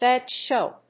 アクセントBád shów! 《英口語》